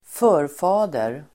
Ladda ner uttalet
Uttal: [²f'ö:rfa:der]